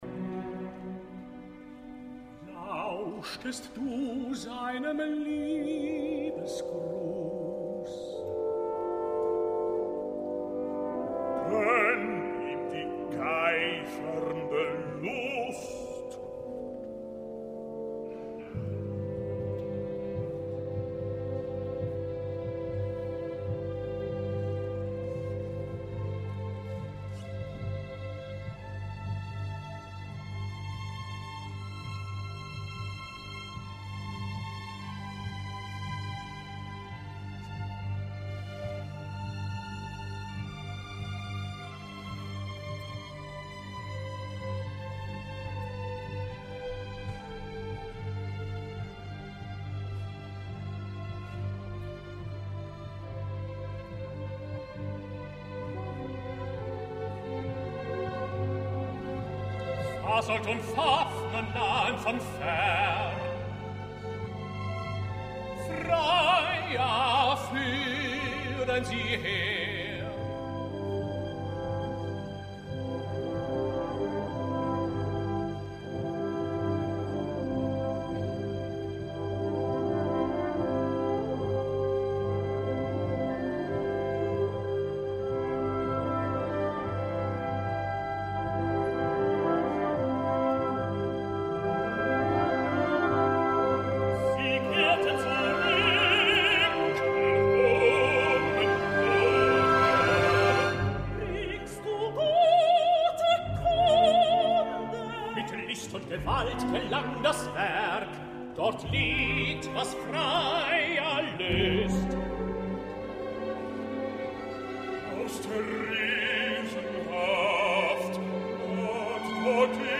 Divendres 24 d’abril va tenir lloc a la sala Hercules de la Residenz muniquesa una concert de l’Orquestra de la Radio Bavaresa sota la direcció de Simon Rattle amb el proleg de la Tetralogia, Das Rheingold en el programa.
És, com ha de ser, un Rheingold molt conjuntat i coral que compta amb una resplendent direcció de Rattle que ofereix una versió de temps lleugers, nerviosa i excitant, poc solemne i amb una exposició transparent i detallista, amb la que l’excel·lent Orquestra de la Ràdio Bavaresa respon amb notabilíssims resultats.